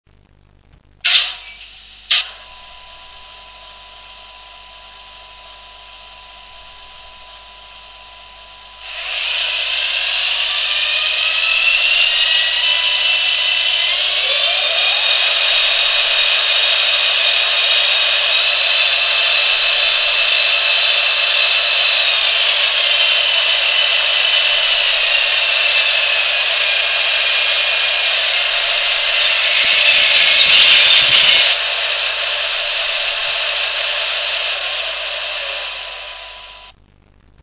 Estos sonidos se han grabado directamente del decoder o módulo una vez instalado en la locomotora.
250motor.mp3